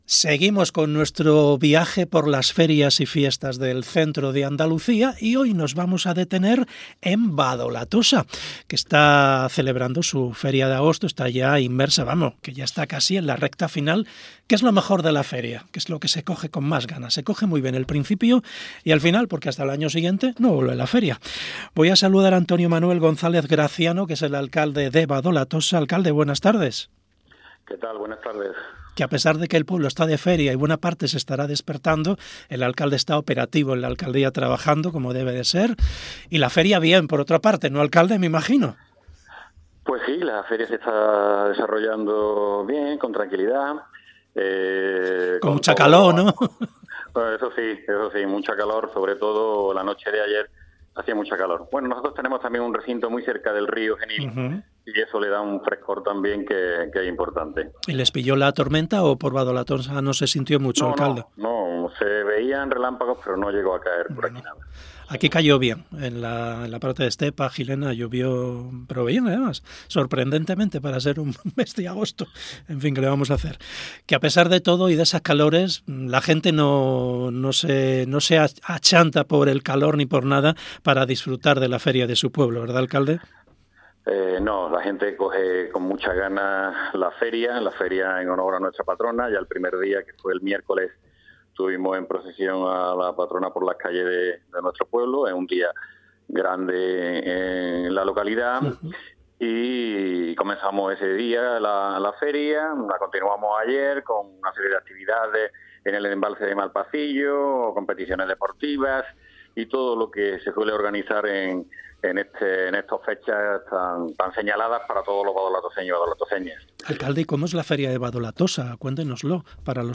Hablamos con su alcalde, Antonio Manuel González Graciano